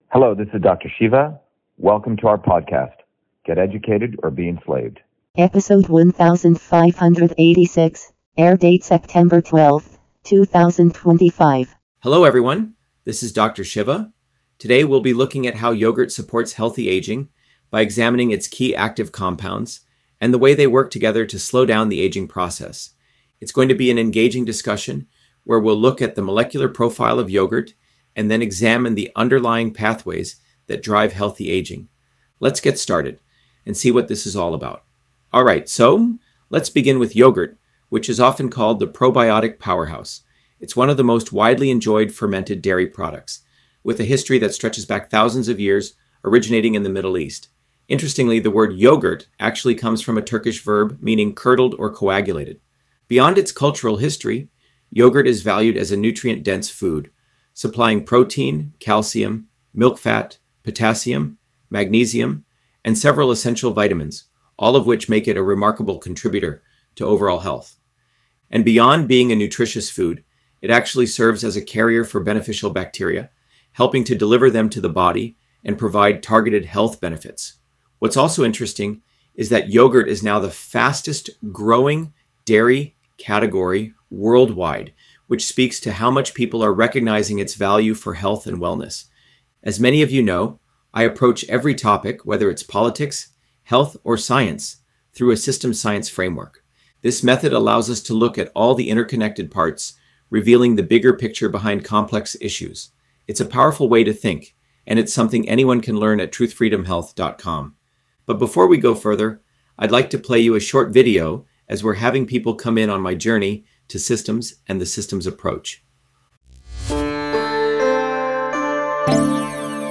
In this interview, Dr.SHIVA Ayyadurai, MIT PhD, Inventor of Email, Scientist, Engineer and Candidate for President, Talks about Yogurt on Aging: A Whole Systems Approach